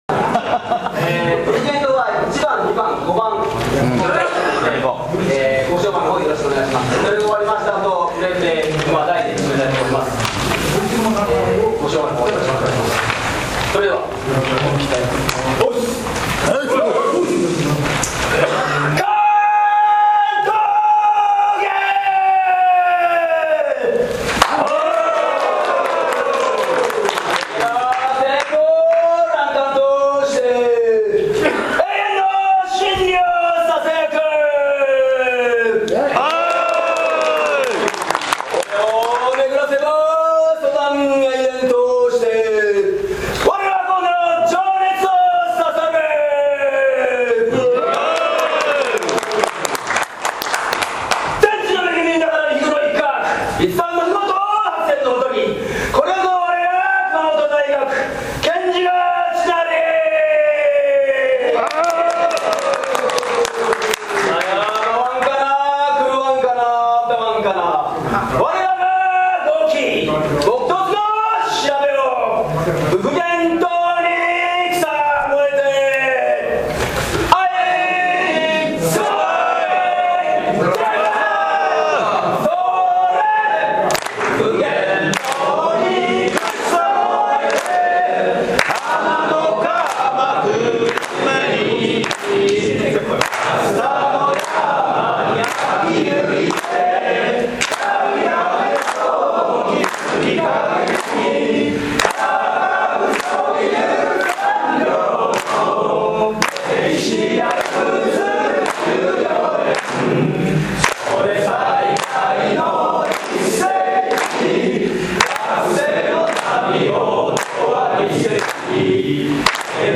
26年度総会も、いよいよ終盤を迎えました。
巻頭言音声記録はこちら